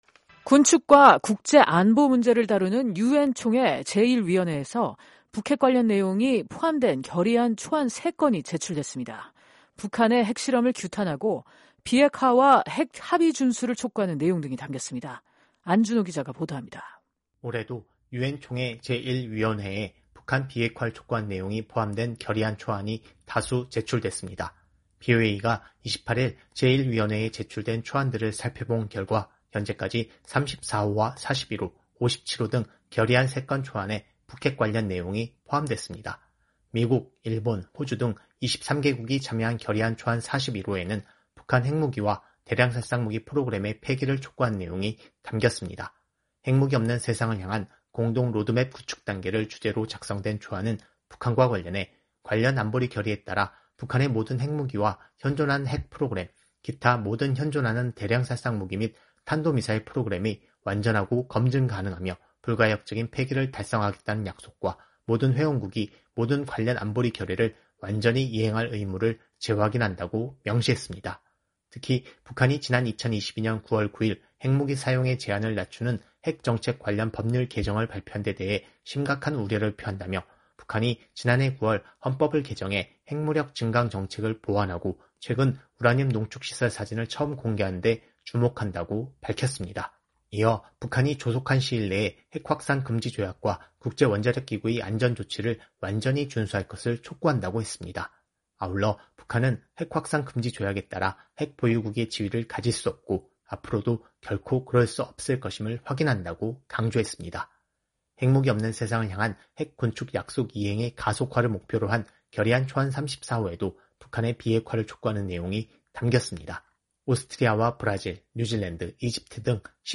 기자가 보도합니다.